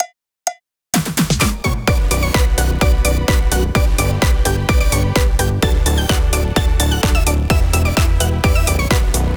פריסט בס לסרום